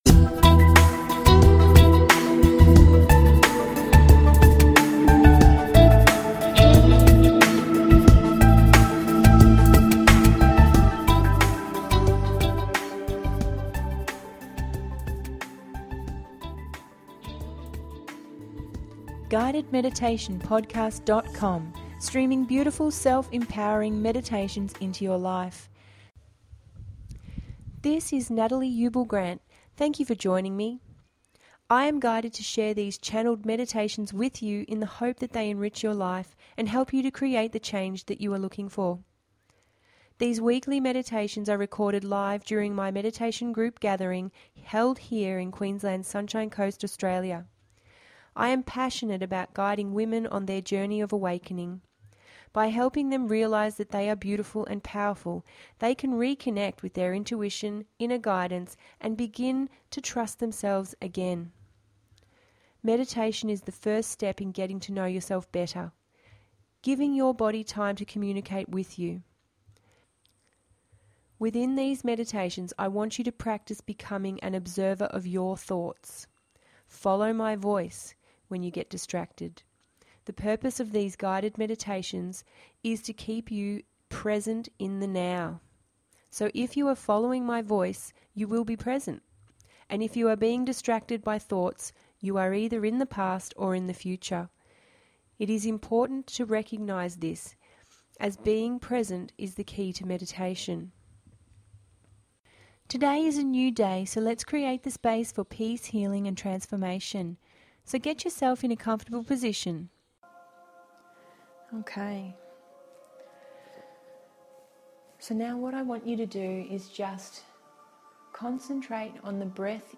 This is a gentle meditation of about 15 mins, enjoy a walk and connect with your awareness.